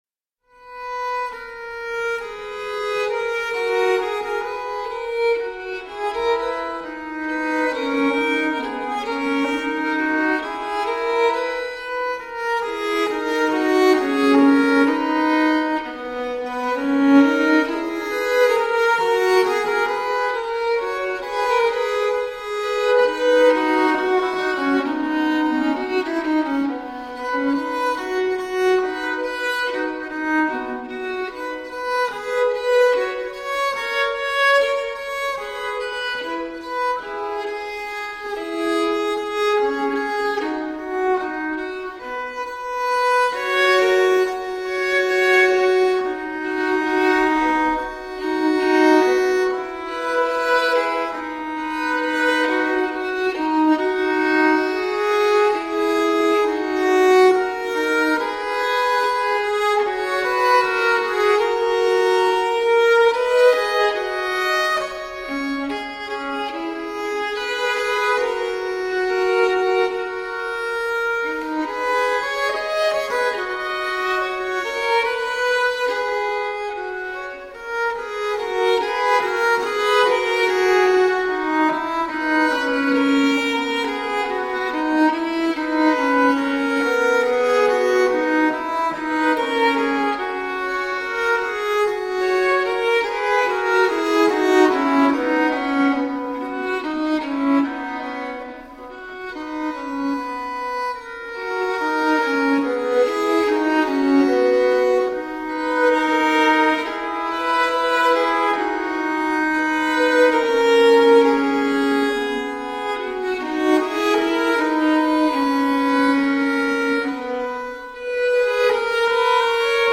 Classical, Renaissance, Baroque, Instrumental
Harpsichord, Violin